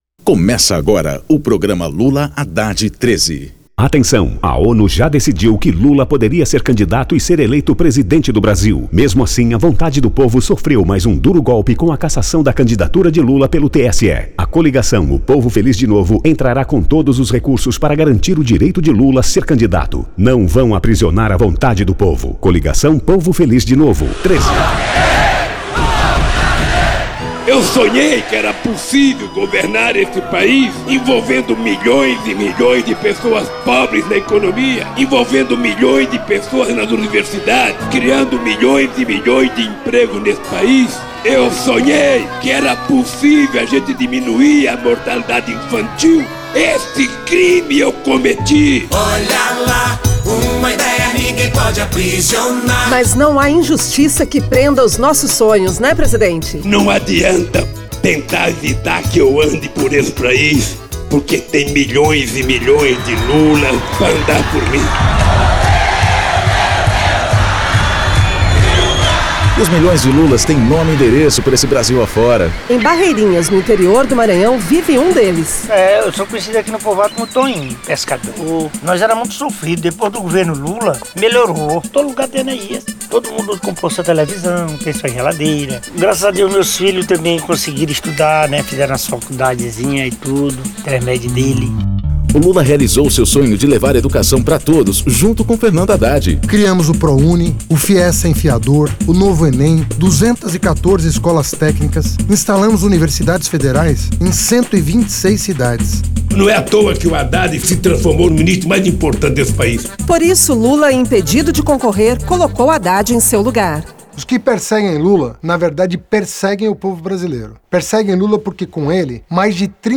Gênero documentaldocumento sonoro
Descrição Programa de rádio da campanha de 2018 (edição 01) - 1° turno